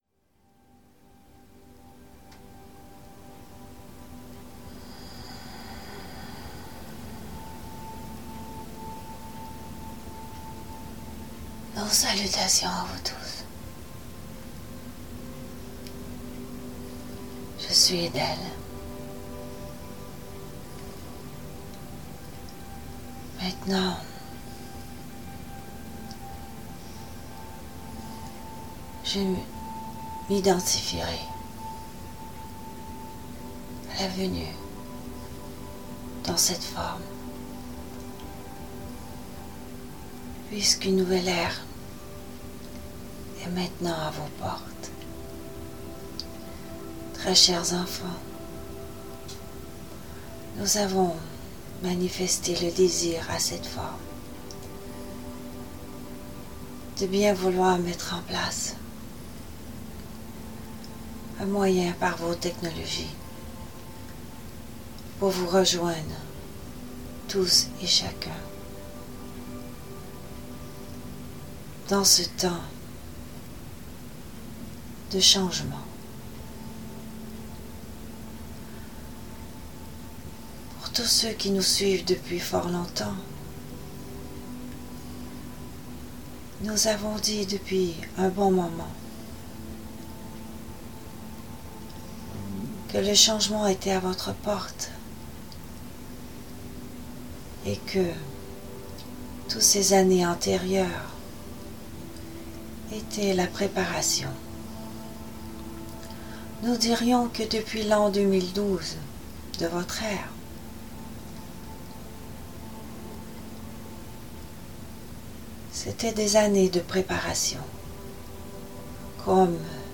Nous sommes désolés pour la qualité sonore qui laisse quelque peu à désirer!